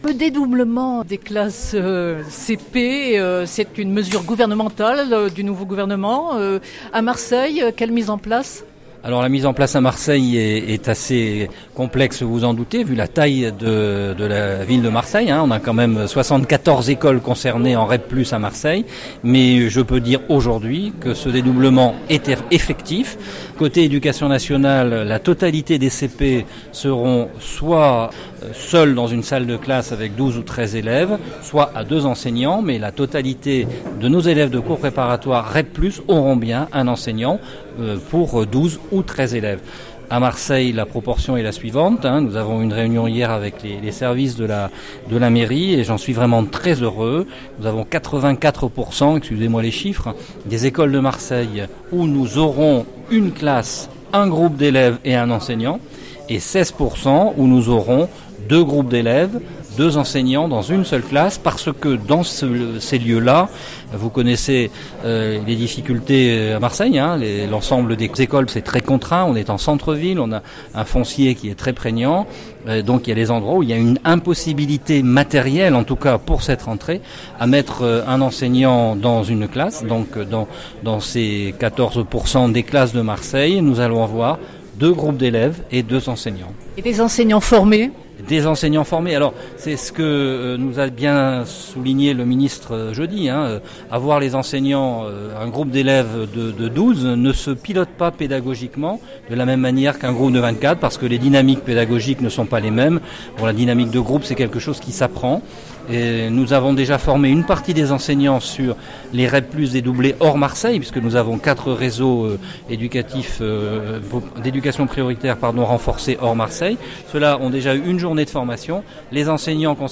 Pour cette Rentrée 2017, près de 2 500 classes de CP des réseaux d’Éducation prioritaire renforcés (REP+), sont concernées au plan national. son_copie_petit-154.jpg Dominique Beck, Inspecteur d’académie – Directeur académique des services de l’Éducation nationale (IA-DASEN) des Bouches-du-Rhône de revenir sur ce dédoublement de classes.